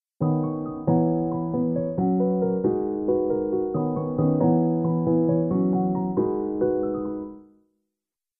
これを、LPFを通すと
なるほど、低い音だけ通して高い音は隠れちゃったからちょっとこもった感じになるのかぁ。